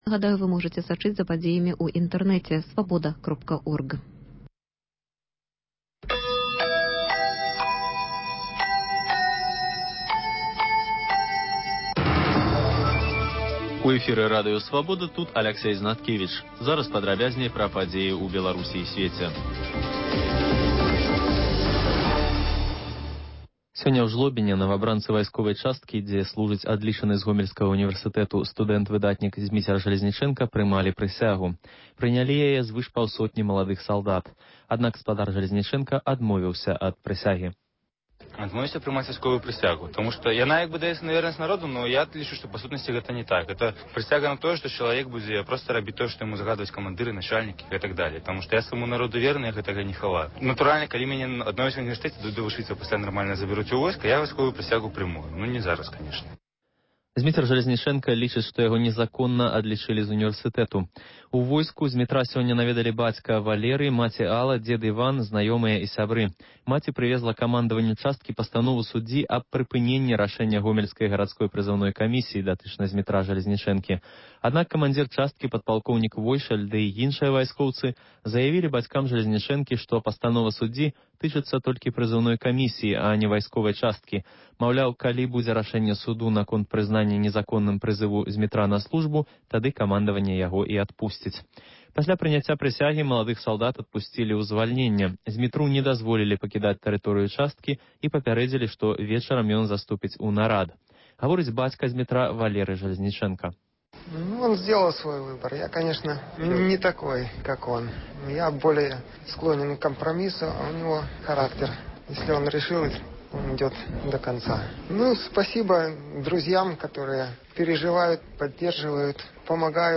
Паведамленьні нашых карэспандэнтаў, званкі слухачоў, апытаньні на вуліцах беларускіх гарадоў і мястэчак.